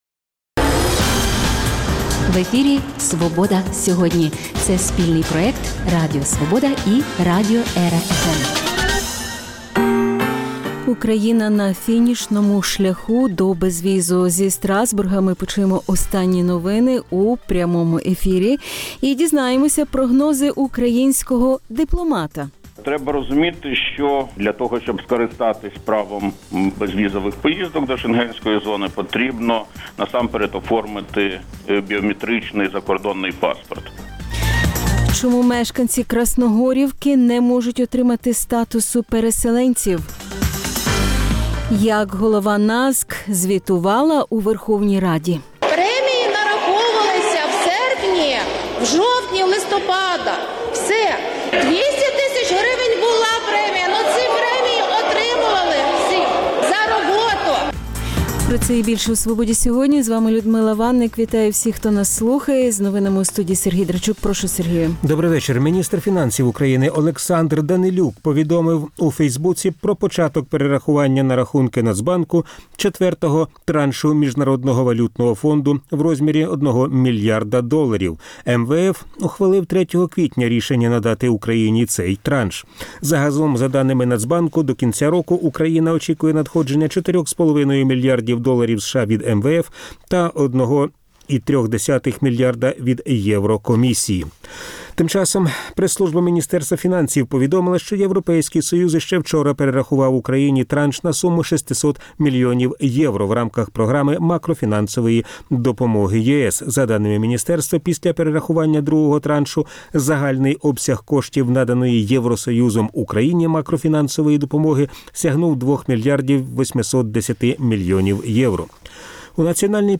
Зі Страсбурга почуємо останні новини у прямому ефірі Чому мешканці Красногорівки не можуть отримати статусу переселенців?